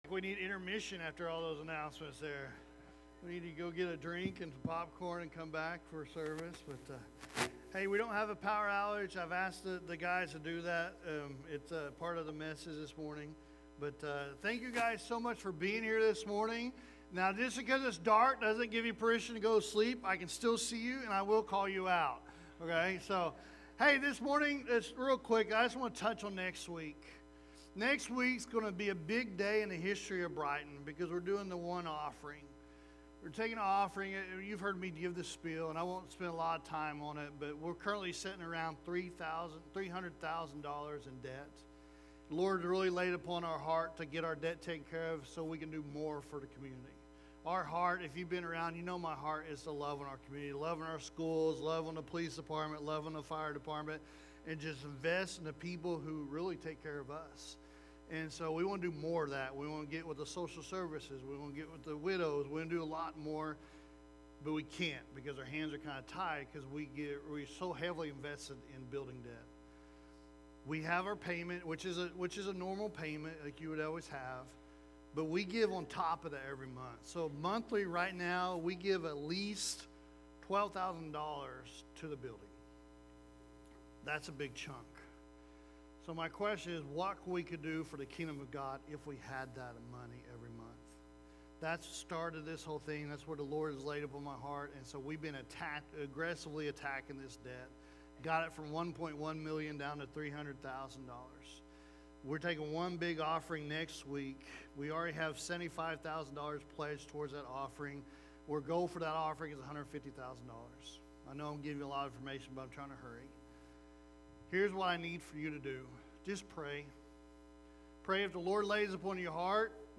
Sermons | Brighton Assembly